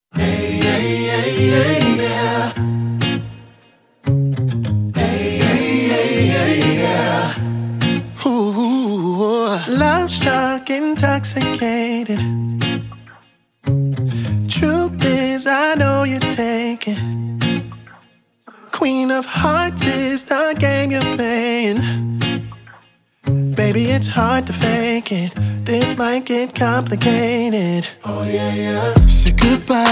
Genre: Neo-Soul